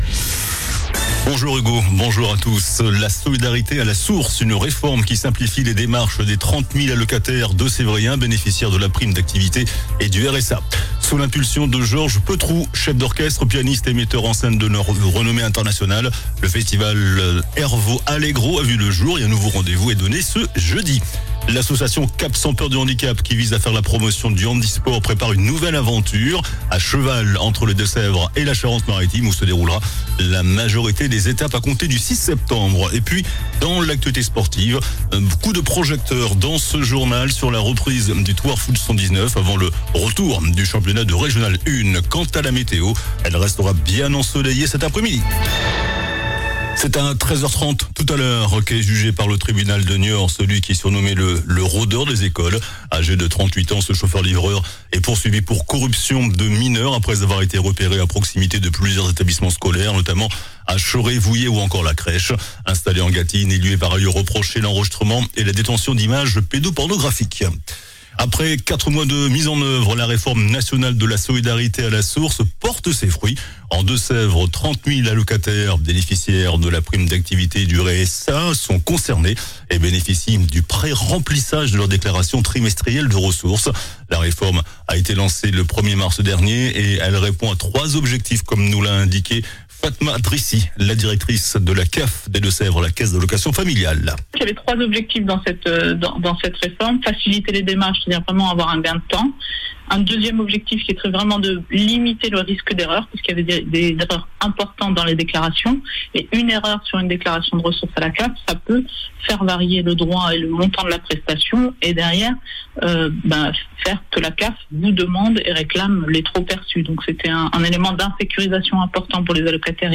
JOURNAL DU LUNDI 28 JUILLET ( MIDI )